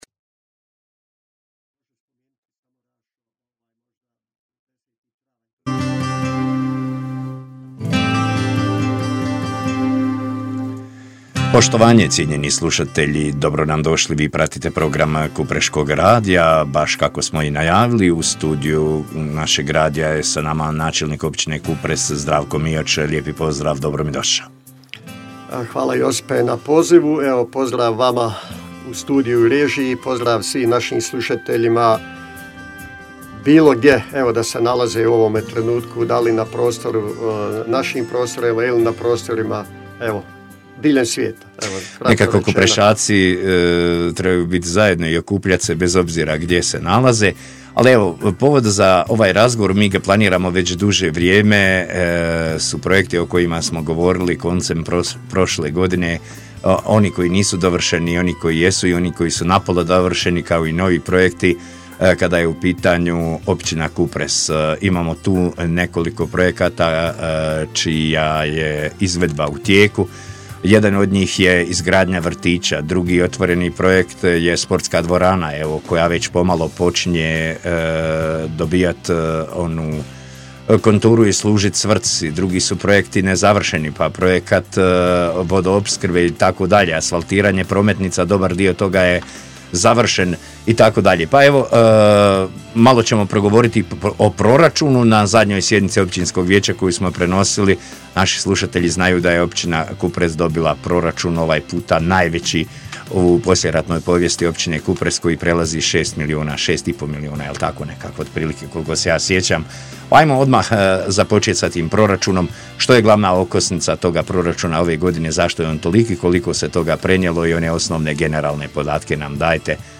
Razgovor s Načelnikom g. Zdravkom Miočem: Aktualni i budući projekti u našoj Općini